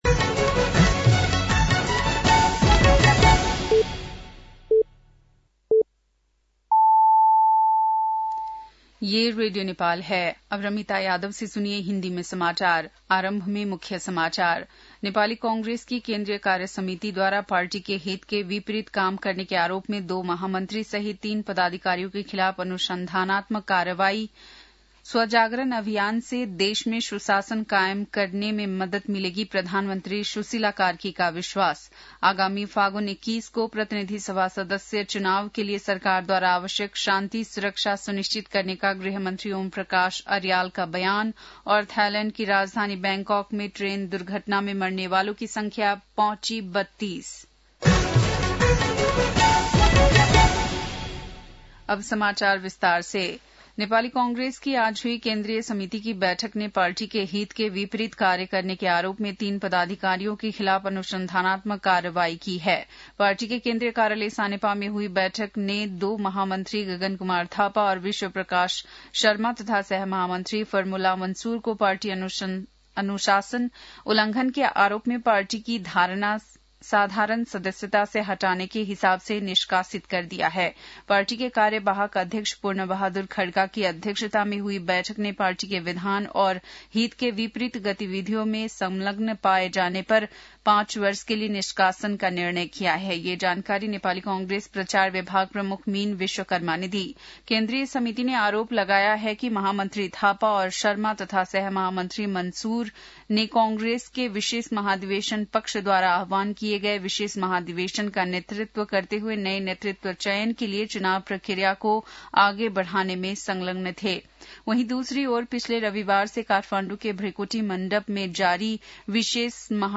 बेलुकी १० बजेको हिन्दी समाचार : ३० पुष , २०८२
10-pm-hindi-news-9-30.mp3